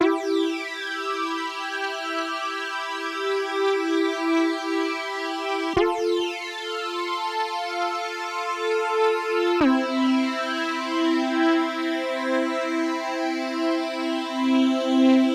悲伤垫子
描述：悲伤的垫子，从我的歌，告诉我你做什么:)
标签： 125 bpm Trap Loops Pad Loops 2.58 MB wav Key : Unknown Ableton Live
声道立体声